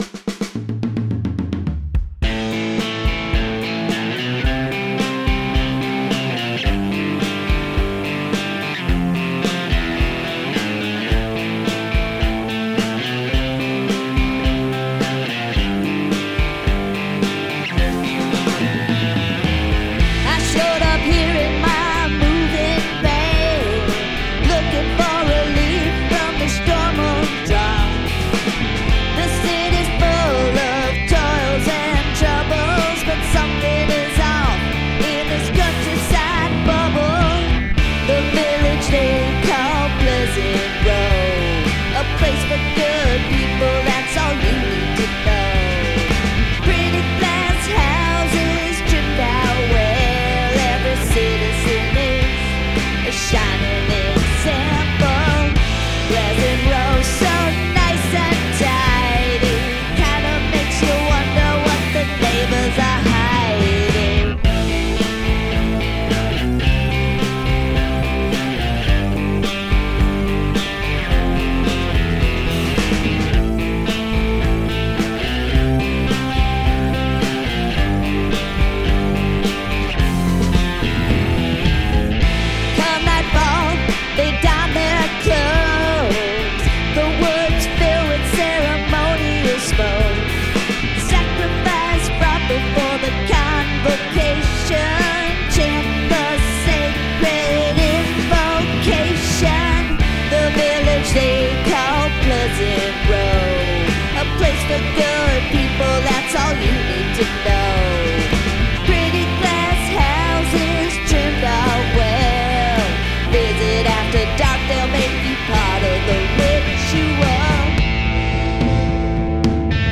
Must include a guest singing or speaking in another language
I like the guitars.